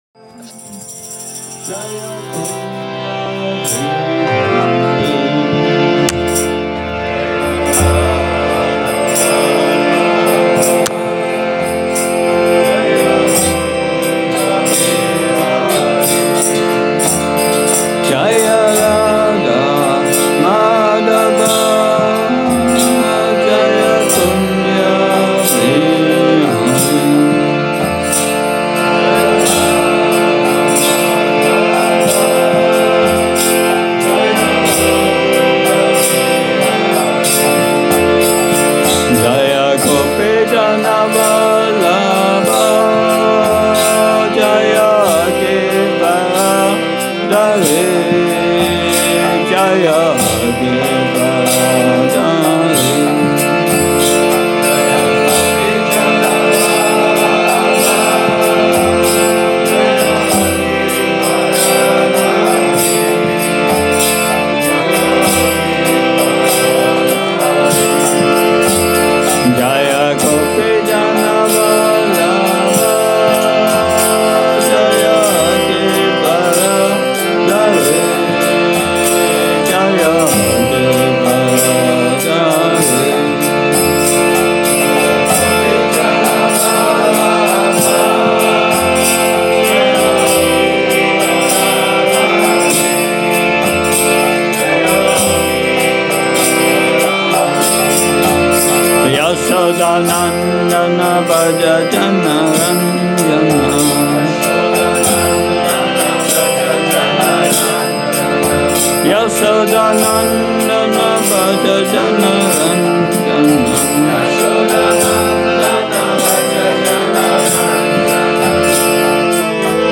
Přednáška BG-4.11 – restaurace Góvinda